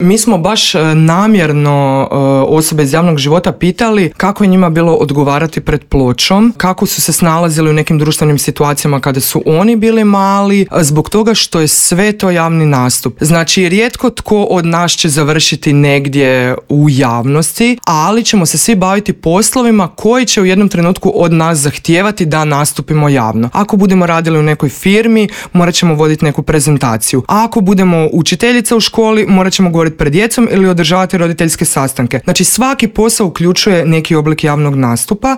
razgovarali smo u Intervjuu Media servisa.